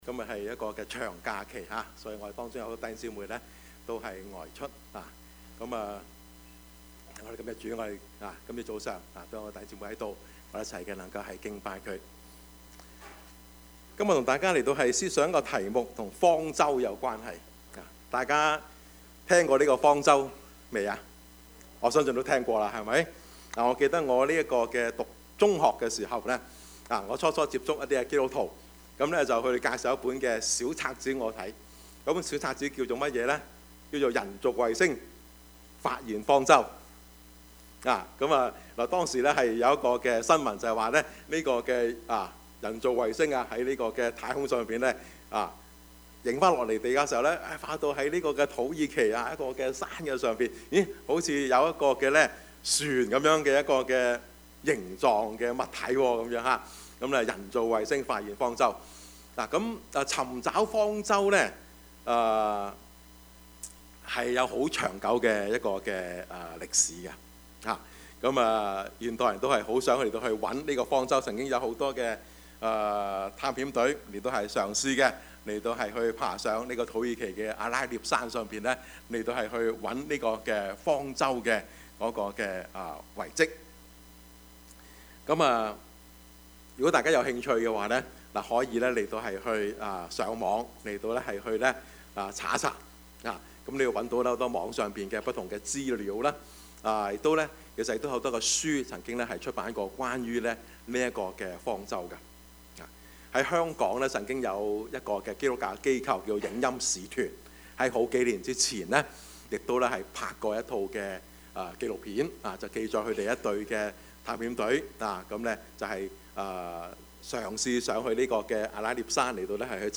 Service Type: 主日崇拜
Topics: 主日證道 « 挪亞的世代 說得合宜 »